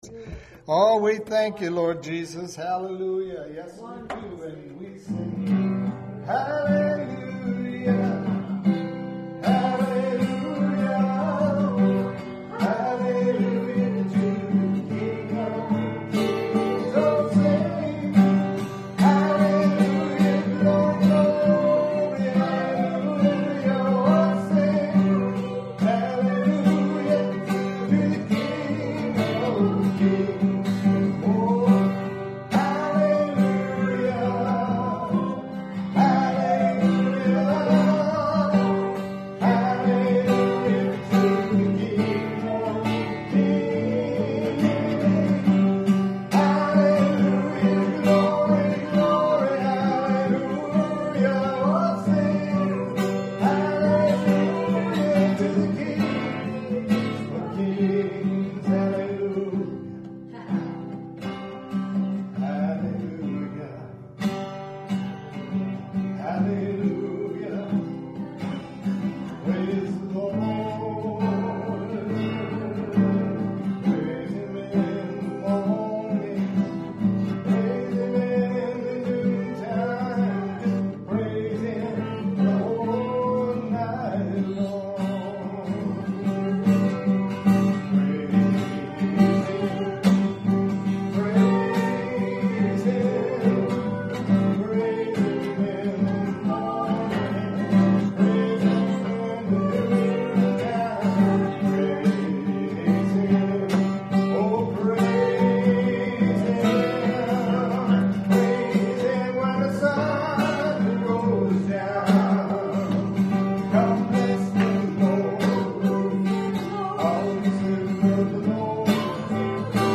WORSHIP11 16.mp3